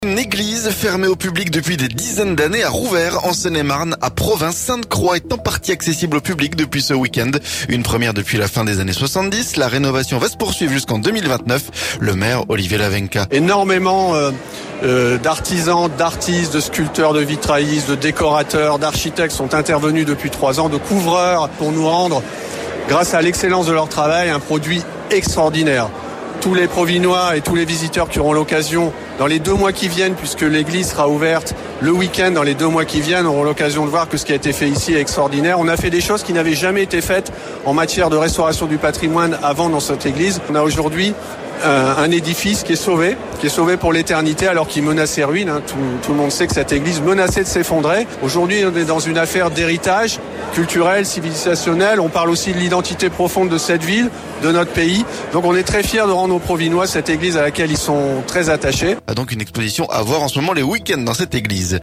A Provins, Sainte-Croix est en partie accessible au public depuis ce week-end, une première depuis la fin des années 70. La rénovation va se poursuivre jusqu'en 2029. Le maire, Olivier Lavenka.